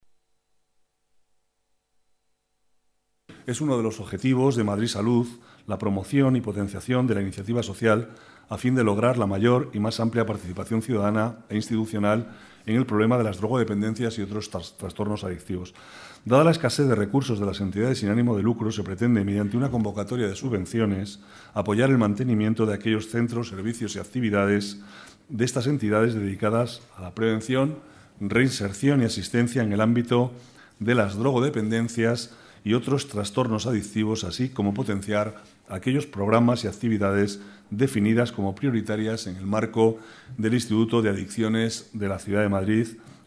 Nueva ventana:Declaraciones del vicealcalde, Manuel Cobo: Ayudas a ONGs con programas contra la drogadición